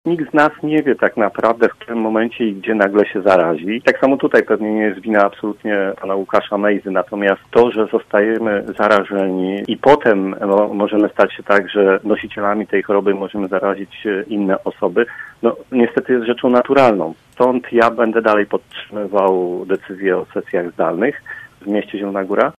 Mówił o tym dziś w „Rozmowie Punkt 9” Piotr Barczak.
Przewodniczący zielonogórskiej Rady Miasta uważa, że trzeba minimalizować ryzyko: